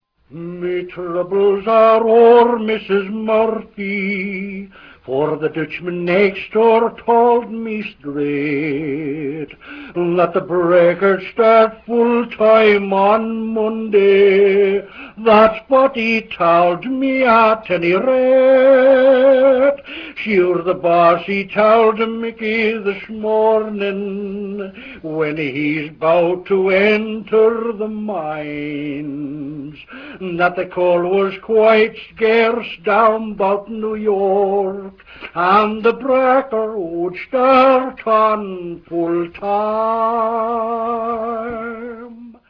Me Johnny Mitchell Man, written by Con Carbon for the same strike, has an equally inspiring message.  Its particular novelty, however, lies in the fact that it was written (and is sung here) in Slavic-American dialect, or a stagey approximation thereof, in order to win Slavs to the union cause and prevent strike-breaking, and to stop attacks by Irish and Welsh miners on Slavic fellow-workers.
Excellent sound quality.